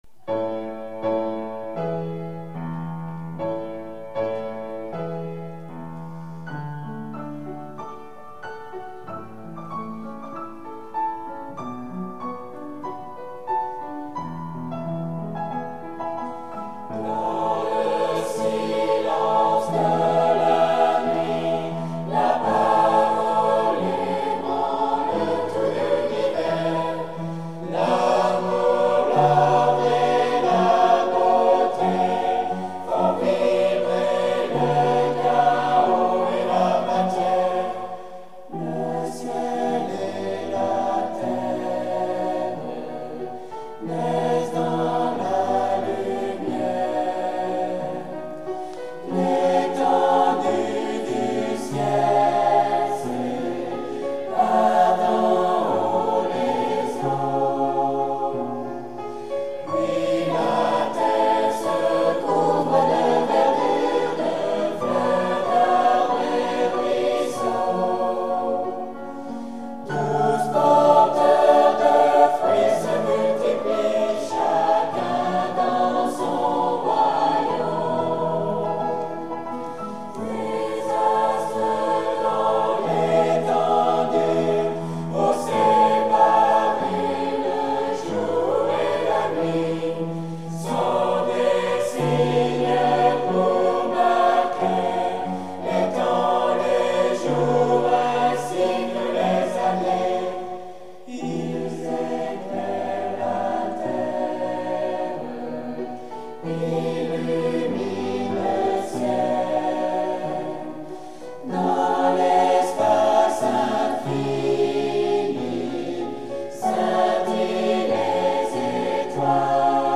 Explorations et Eveil Spirituels : 10 Oeuvres pour Choeur et Piano
Choeur Angers LDS